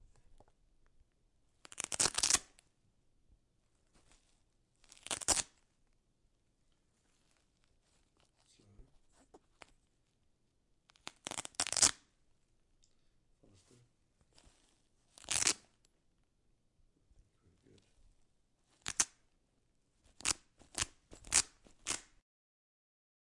描述：魔术贴绑带
Tag: 撕裂 撕裂 魔术贴 翻录 撕裂 面料 OWI